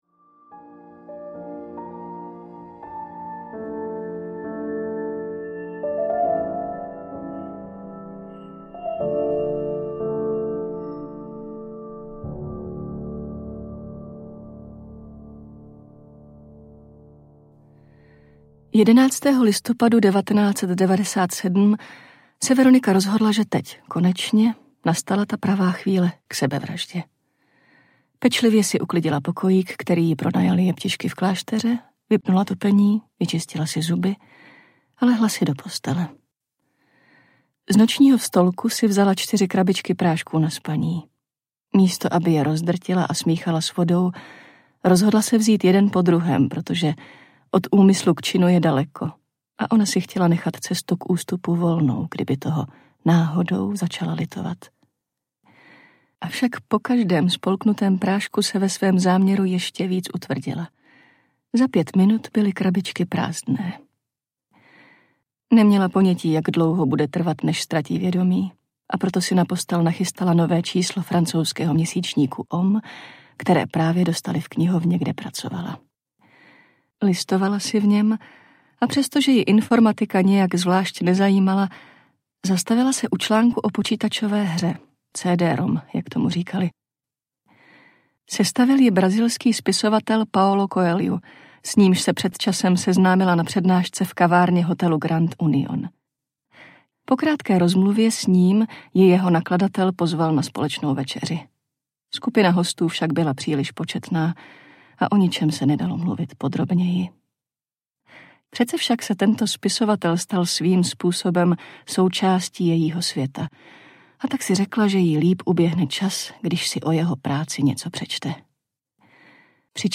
Veronika se rozhodla zemřít audiokniha
Ukázka z knihy
veronika-se-rozhodla-zemrit-audiokniha